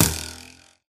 bowhit3